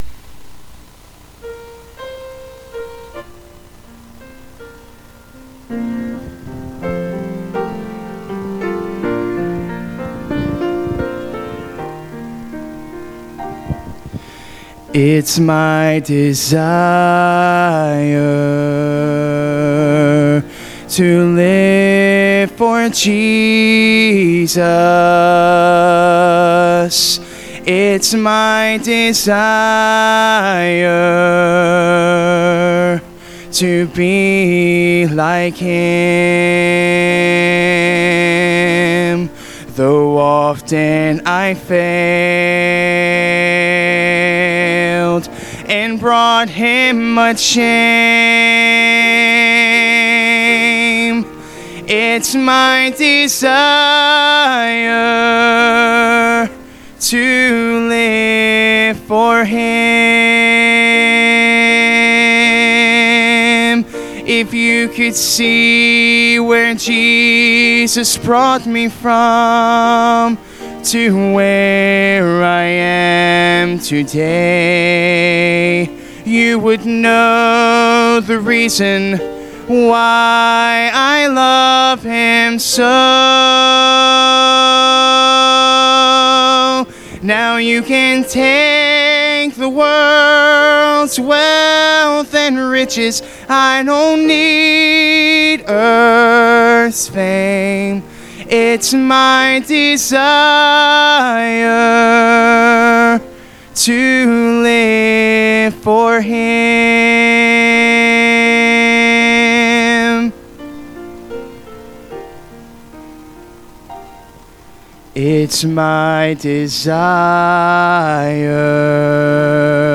Sermons Archive • Page 99 of 166 • Fellowship Baptist Church - Madison, Virginia